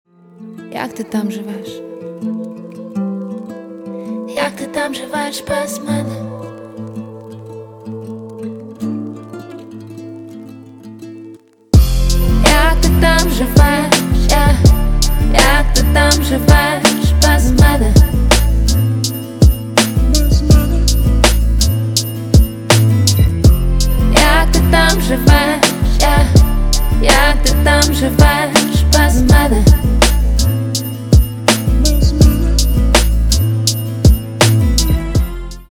Поп Музыка
спокойные # грустные